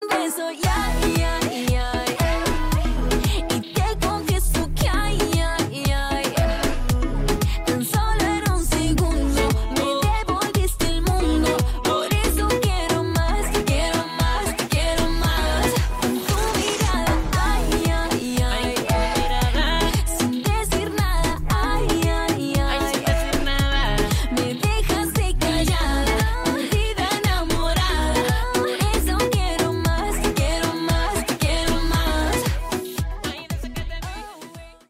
Tonos de canciones del POP
es una explosión de energía y romance